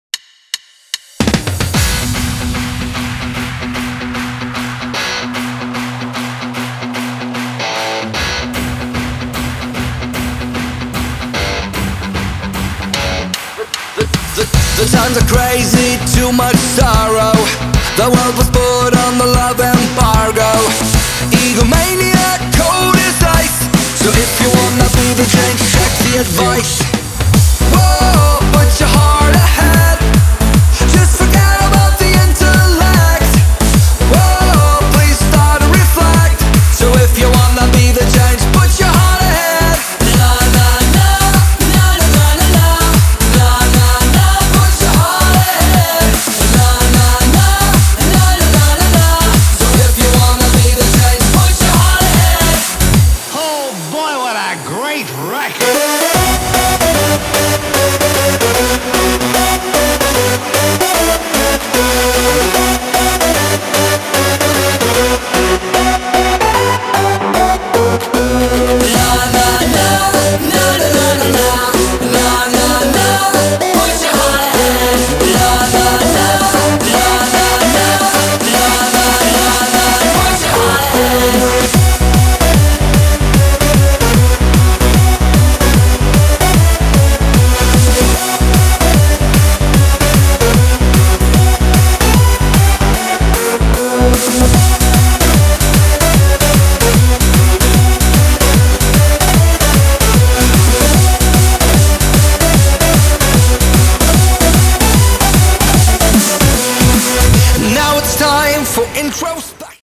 Happy Hardcore